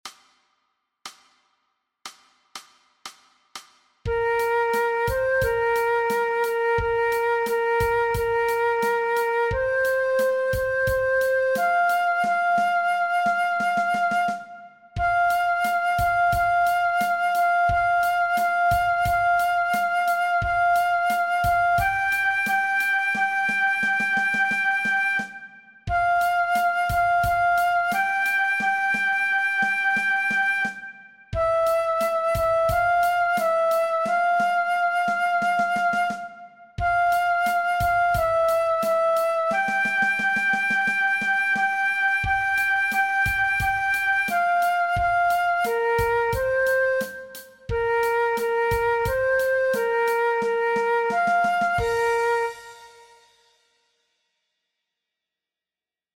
Aangepaste oefening NT 4.1 Dwarsfluit
Dwarsfluit aangepast
MEESPEELTRACK-NT-4.1-Fluit-alternate.mp3